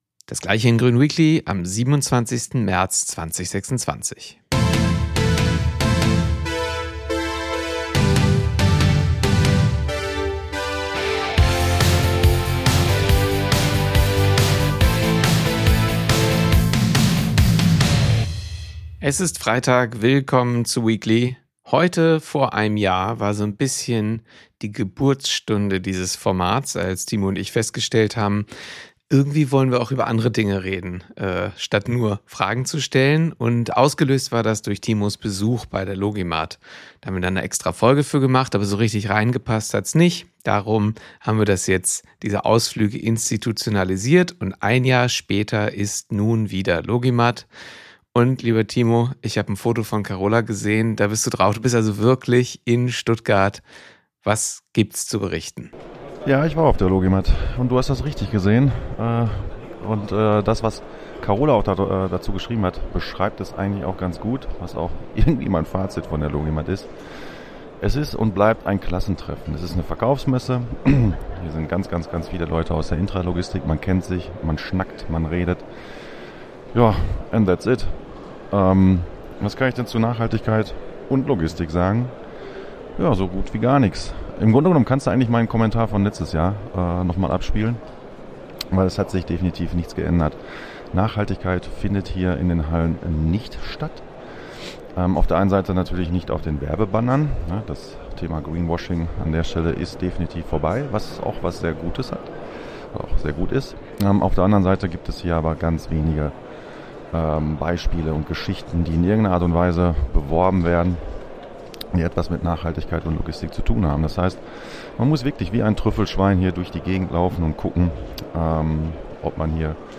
Grund genug unseren Ü-Wagen nach Süden zu schicken.